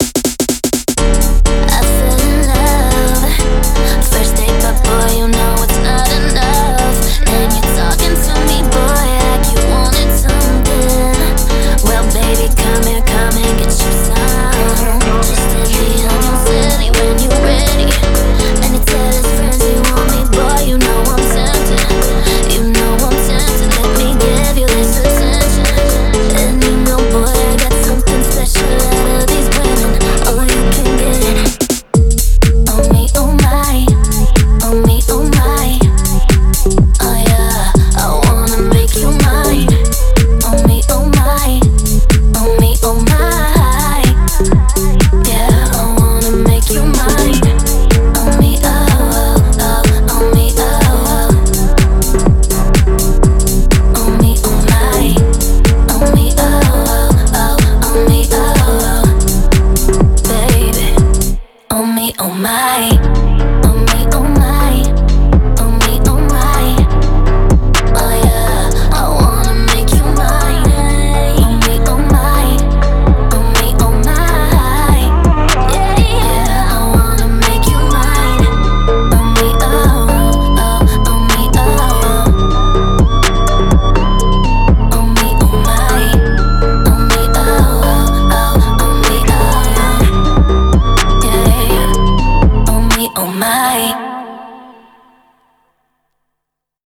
BPM62-124
Audio QualityMusic Cut